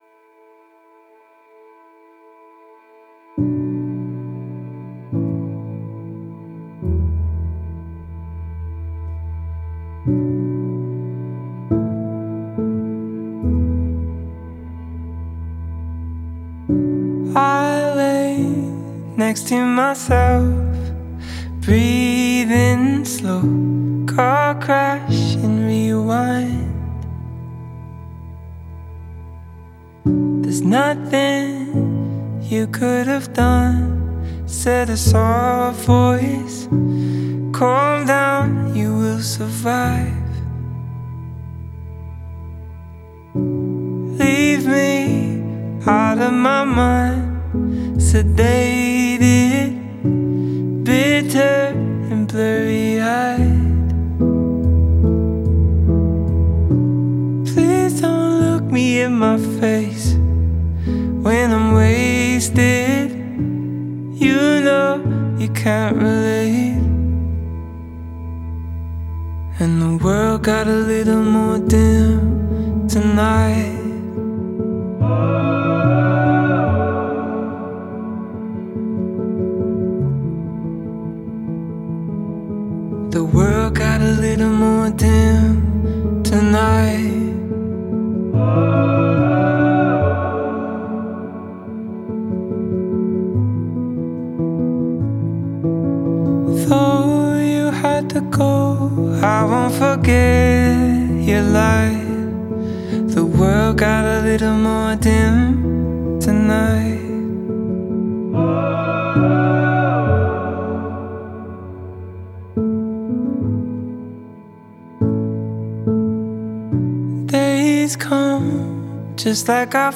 Alternative/Indie